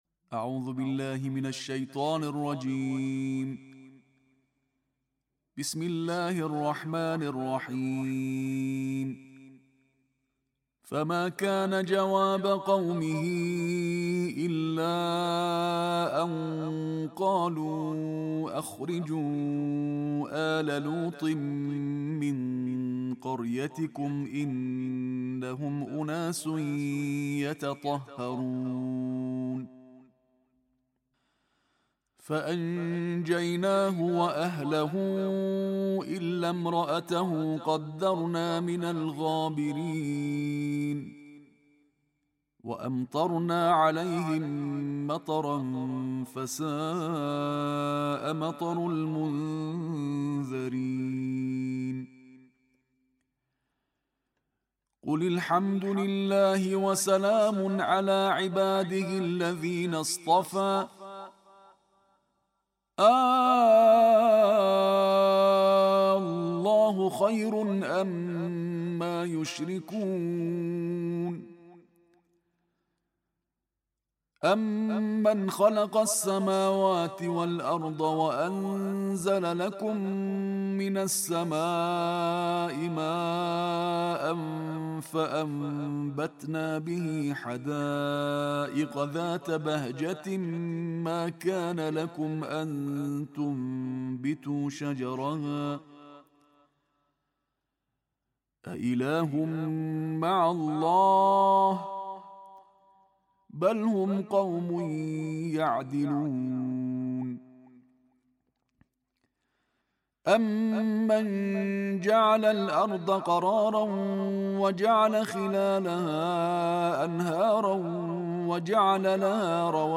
Каждый день с Кораном: Тартиль двадцатого джуза Корана